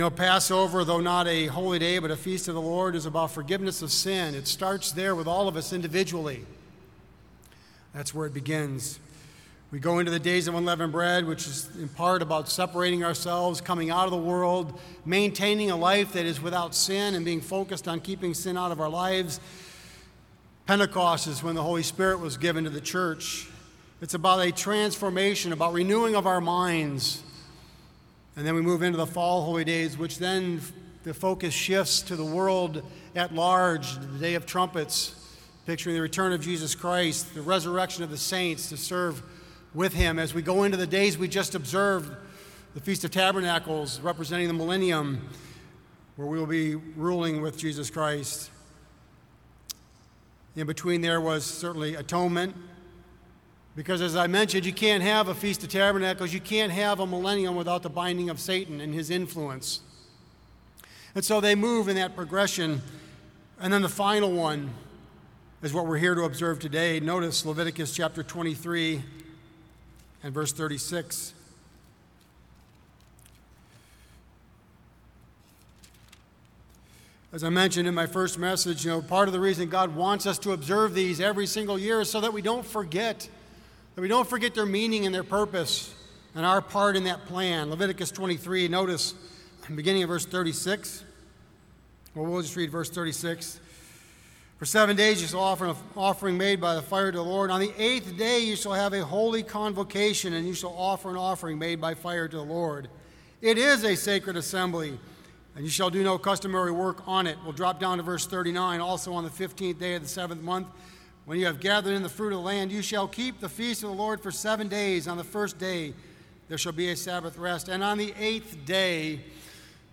This sermon was given at the Gatlinburg, Tennessee 2020 Feast site.